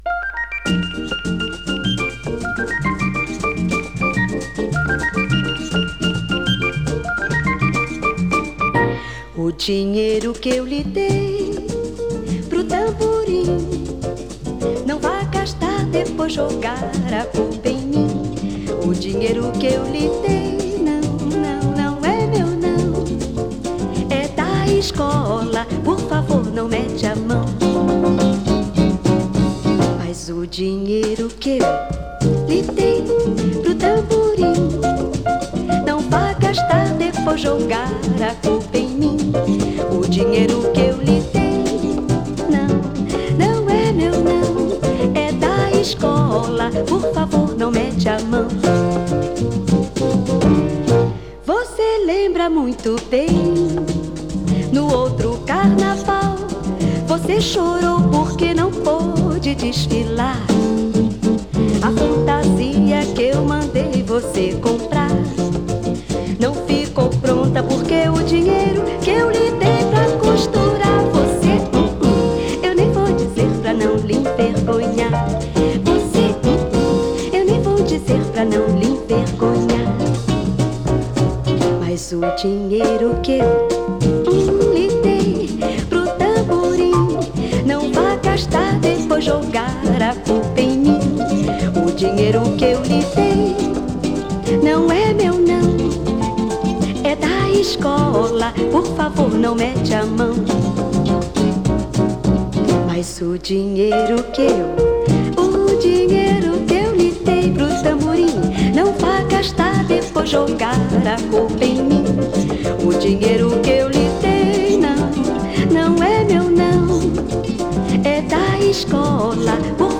sugary rendition